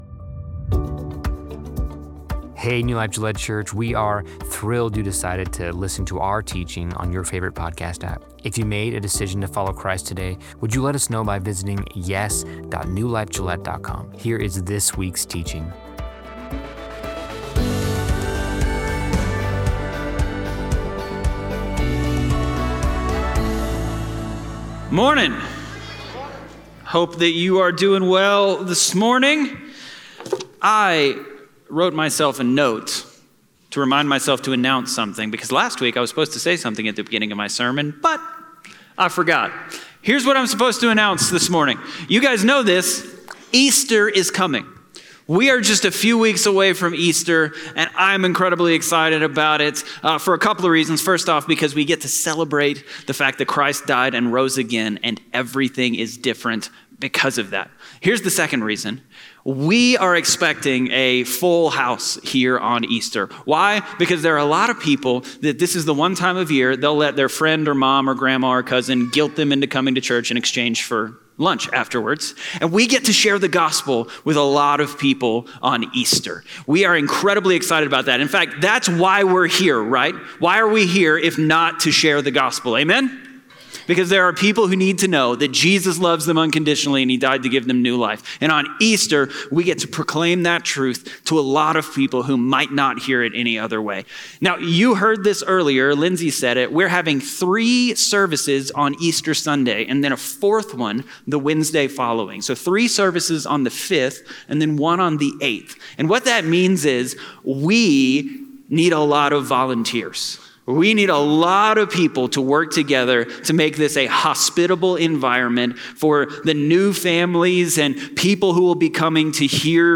Live from Meaning